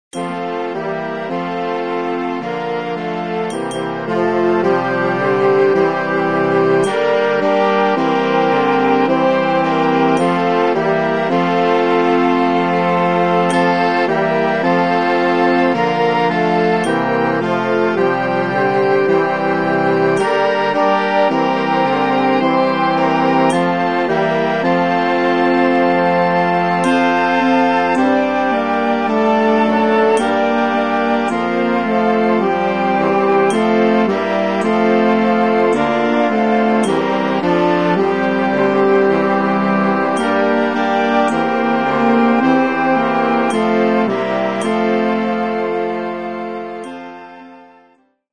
pasyjna